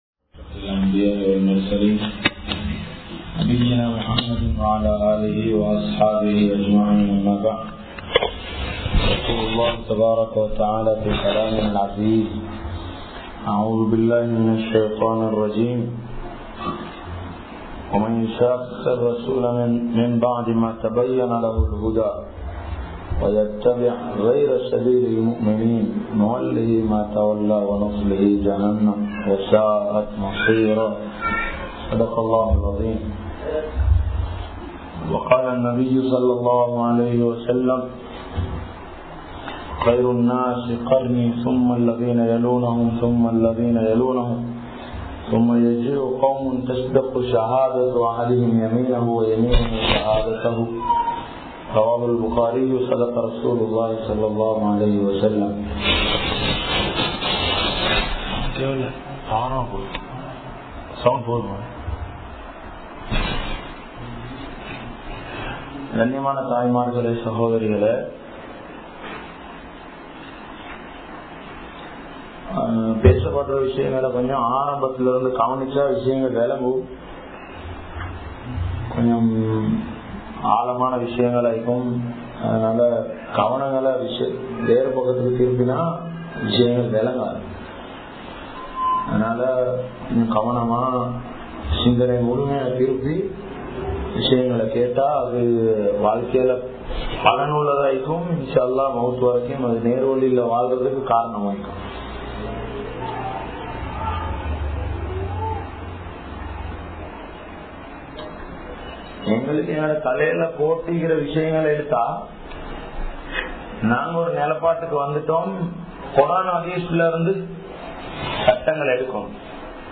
Neraana Vali Ethu? (நேரான வழி எது?) | Audio Bayans | All Ceylon Muslim Youth Community | Addalaichenai